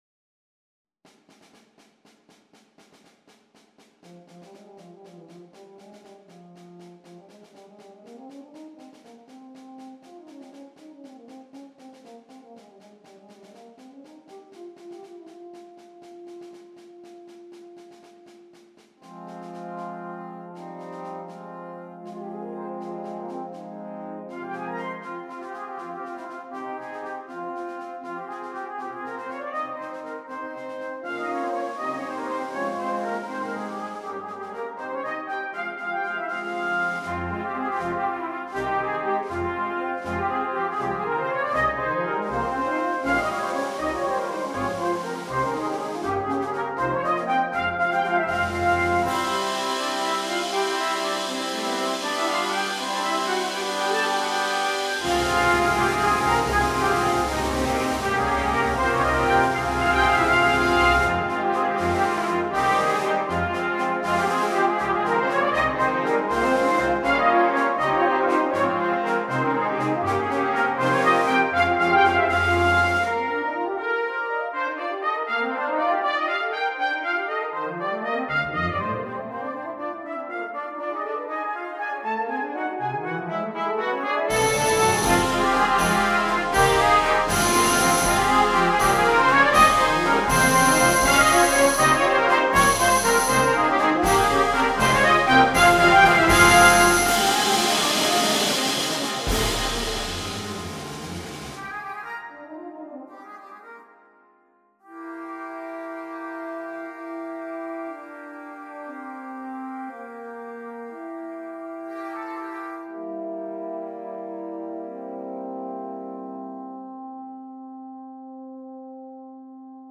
Banda completa
sin un solo instrumento
A Fantasy For Brass Band